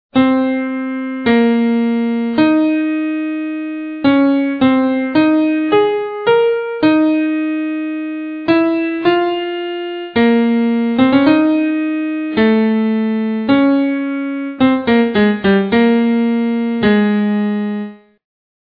Beyond the theme, that single phrase is repeated an octave above.
The key is unambiguously stated from the very beginning of the theme.
The first note extraneous to the key of A flat major is the E natural at bar 3.